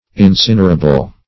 Search Result for " incinerable" : The Collaborative International Dictionary of English v.0.48: Incinerable \In*cin"er*a*ble\, a. Capable of being incinerated or reduced to ashes.